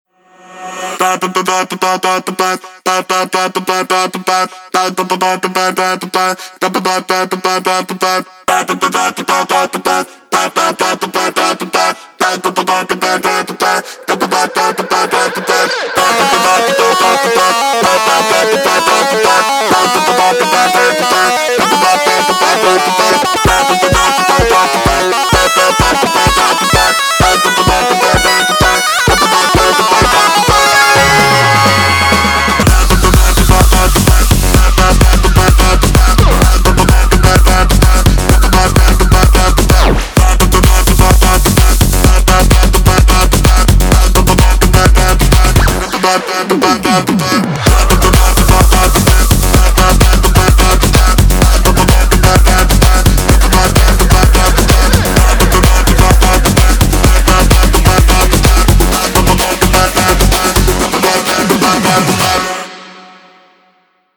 • Качество: 320, Stereo
веселые
заводные
dance
электронная музыка
без слов